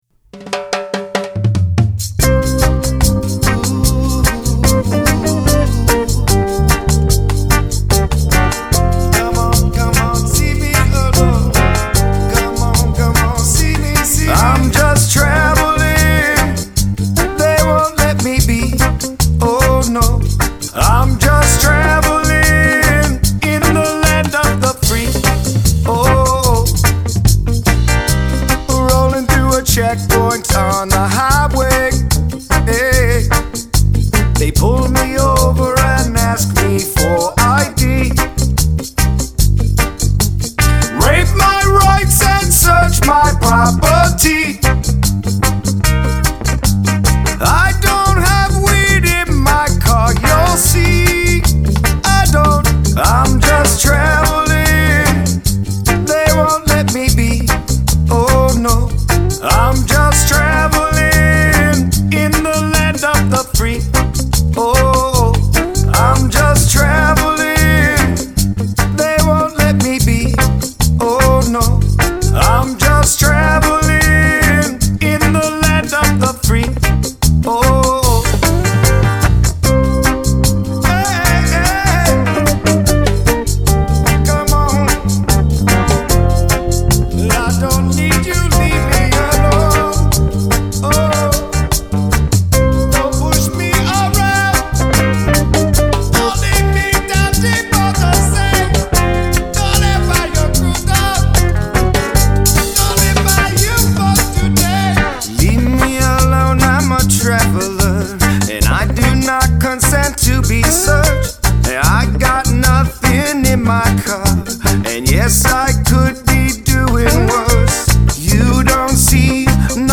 Reggae Single iTunes Coverart